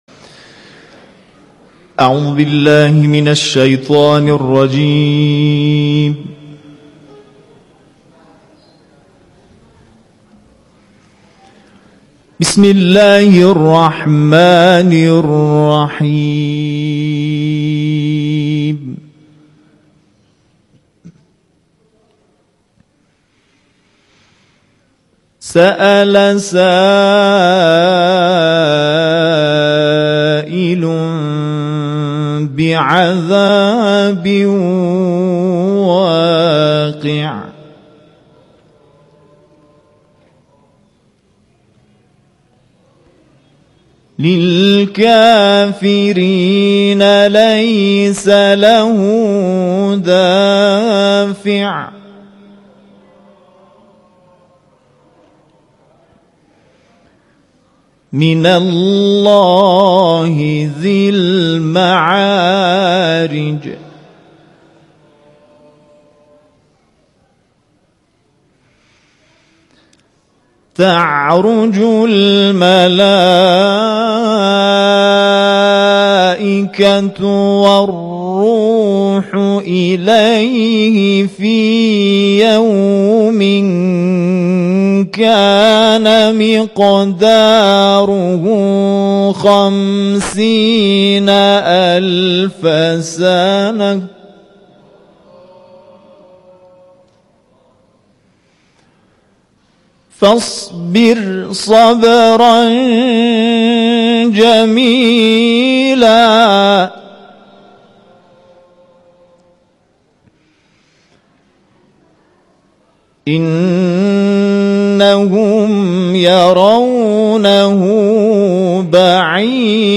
تلاوتی